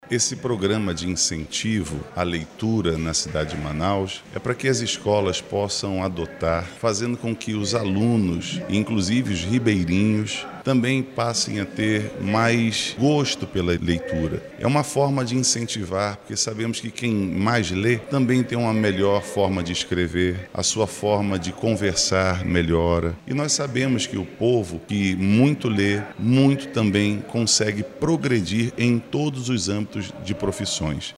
O autor do Projeto de Lei, o vereador João Carlos, do Republicanos, explica que o principal objetivo é estimular o hábito pela leitura, nos estudantes, desde as primeiras fases de aprendizado.